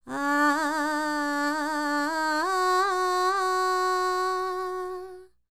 QAWALLI 03.wav